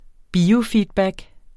Udtale [ ˈbiːofidˌbag ]